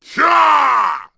Jin-Vox_Attack6_kr.wav